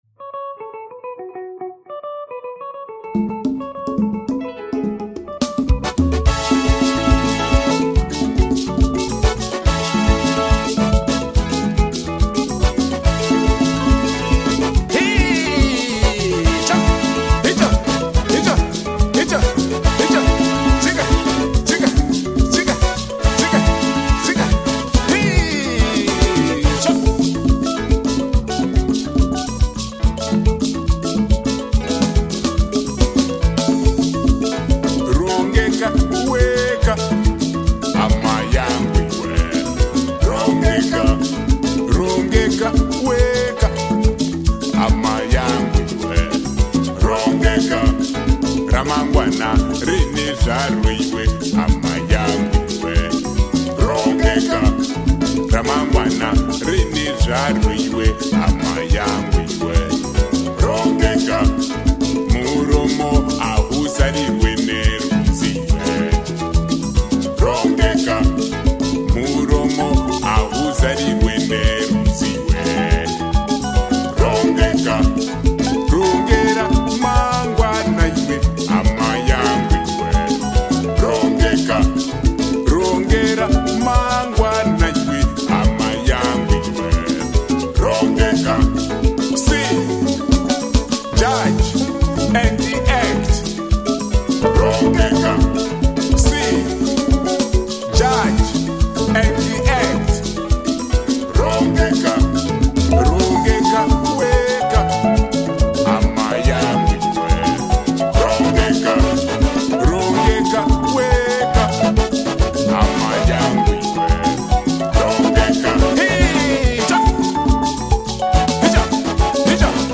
Vi spelar LEVANDE DANS-MUSIK FRÅN ZIMBABWE.
• Folkmusik/världsmusik